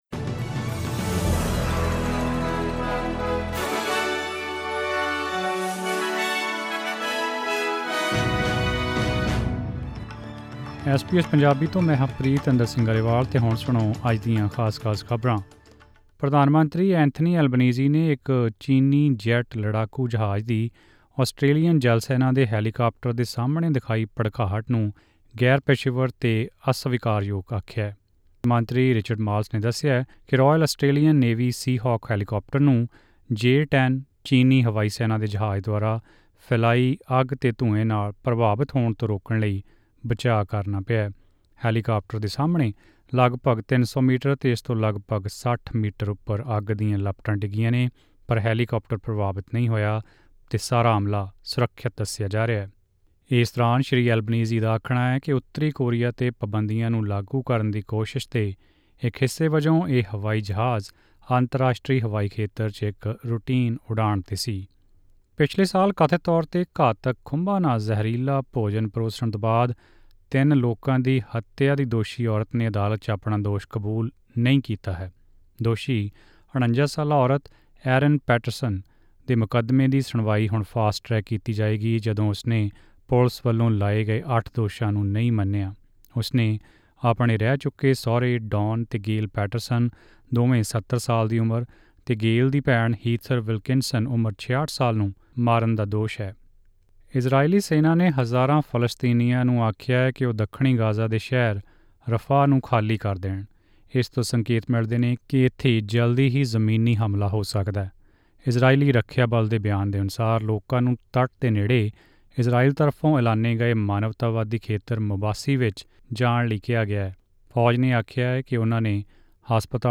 ਐਸ ਬੀ ਐਸ ਪੰਜਾਬੀ ਤੋਂ ਆਸਟ੍ਰੇਲੀਆ ਦੀਆਂ ਮੁੱਖ ਖ਼ਬਰਾਂ: 7 ਮਈ, 2024